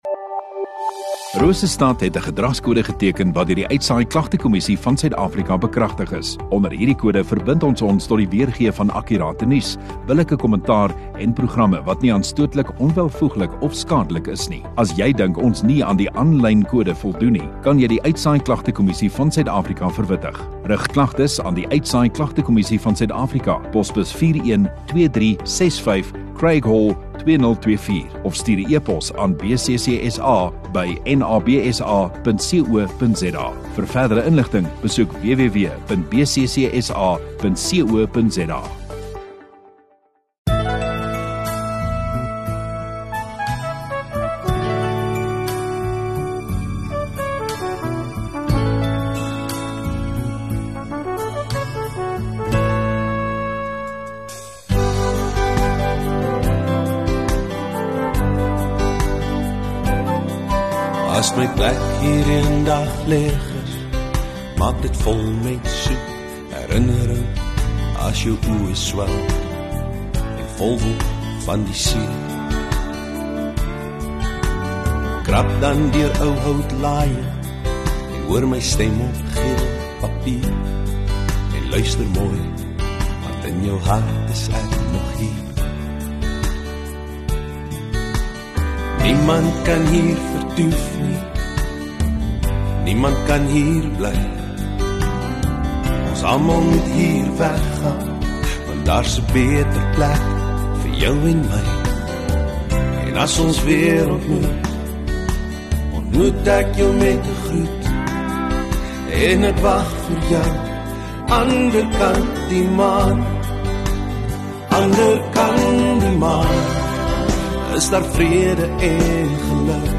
24 May Saterdag Oggenddiens